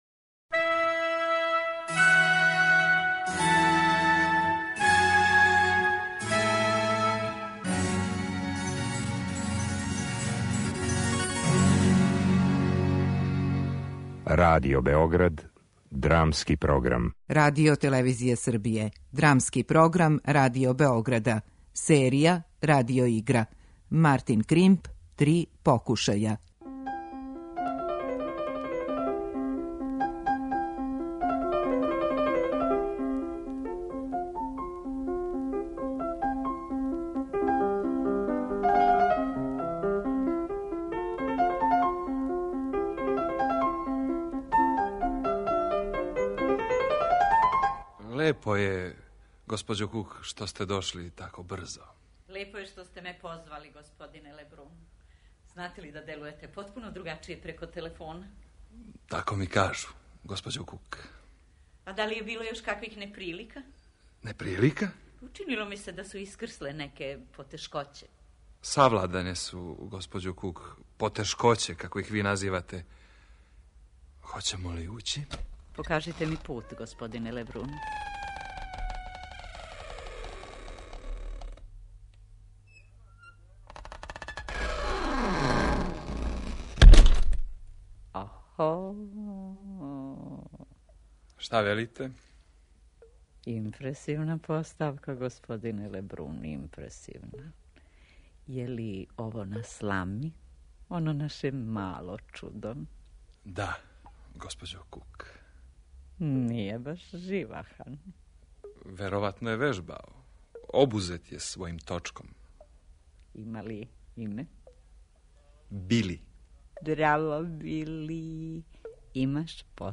Радио-игра
У оригиналној дуо-драми аутора Мартина Кримпа, једног од најистакнутијих представника пост-драмског правца британске драматургије, мушкарац и жена испитују анксиозност непознатог озвученог ентитета.